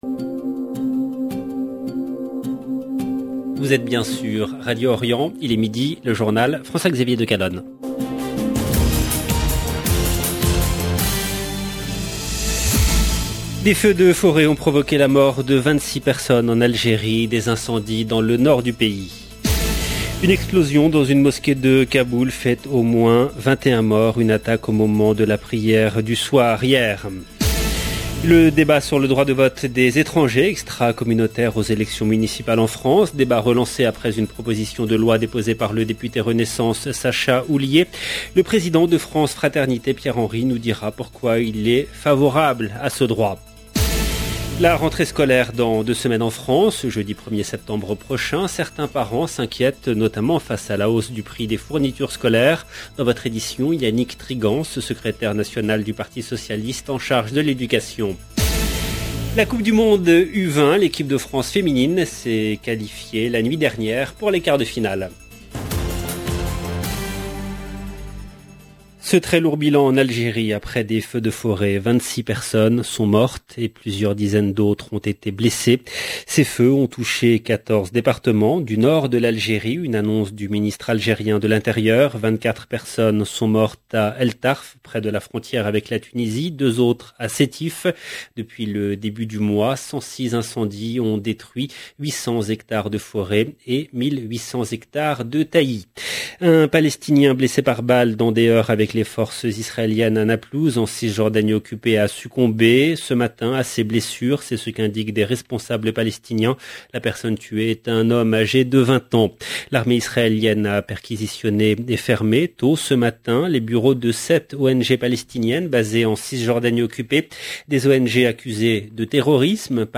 LE JOURNAL DE 12H EN LANGUE FRANCAISE DU 18/8/2022